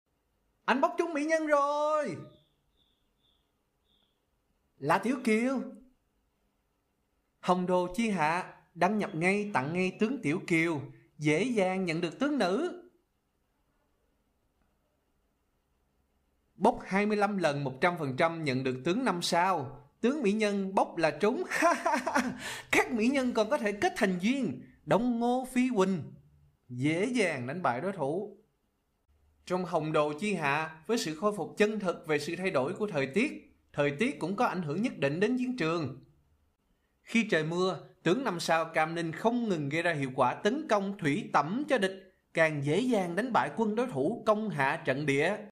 游戏配音